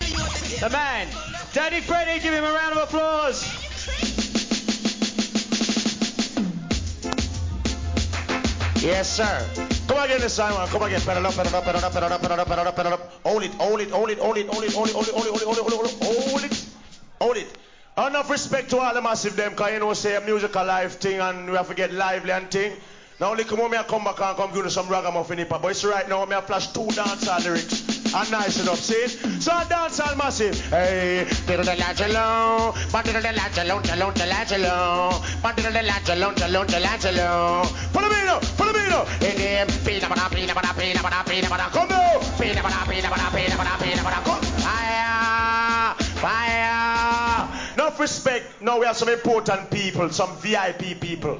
REGGAE
1989年 LIVE音源!!